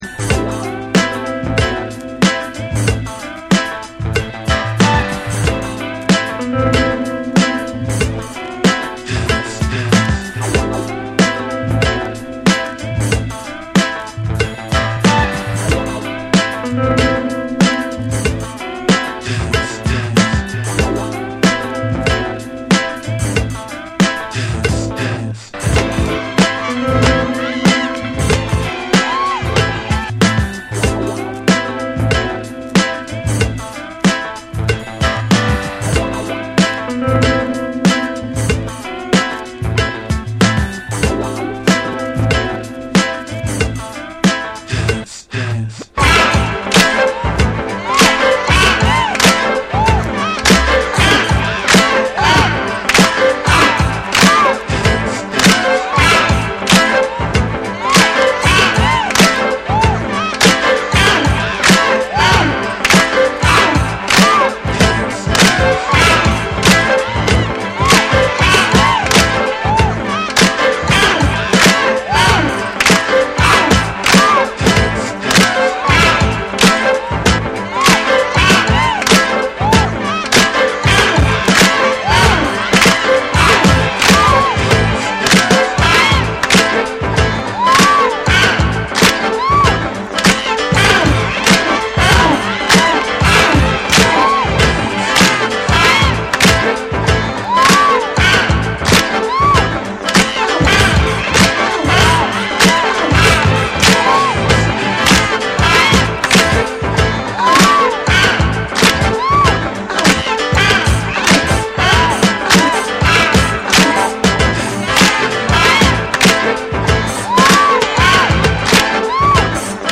BREAKBEATS / RE-EDIT / MASH UP